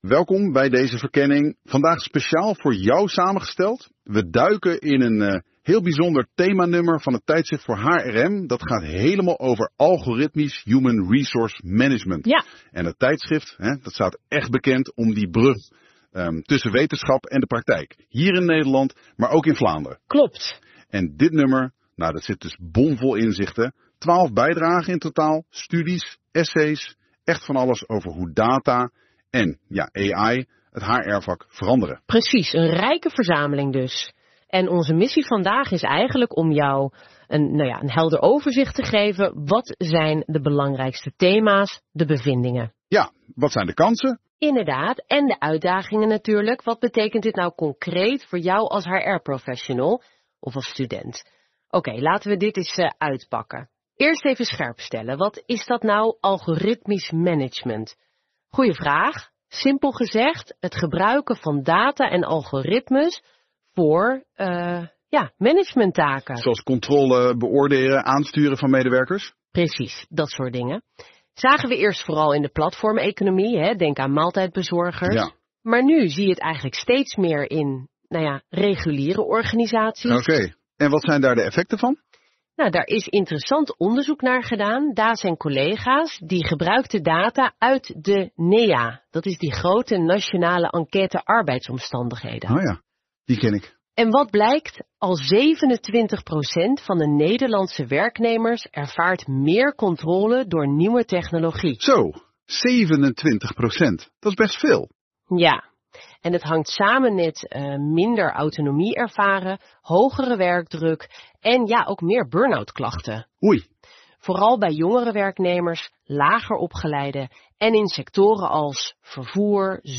Om een eerste indruk te krijgen, kun je luisteren naar onze AI-gegenereerde podcast – een informele audiosamenvatting van de 12 bijdragen, gemaakt met Google’s NotebookLM.